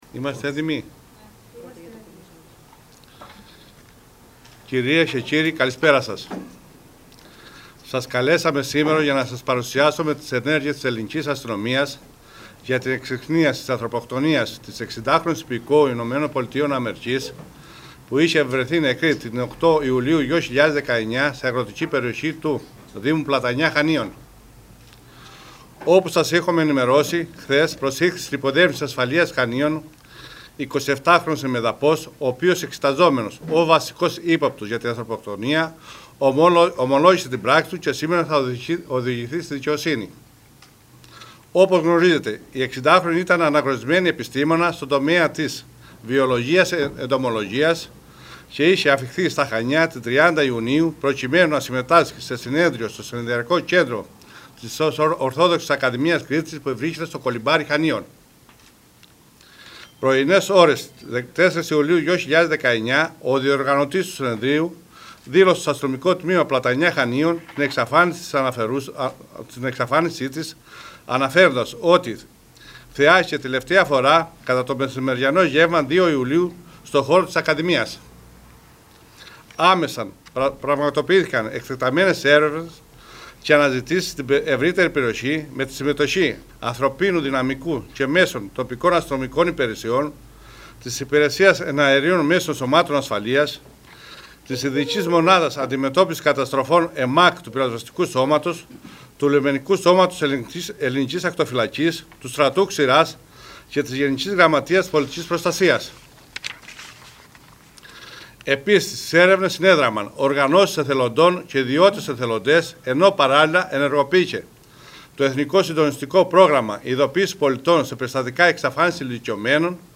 Ακούστε τη συνέντευξη τύπου: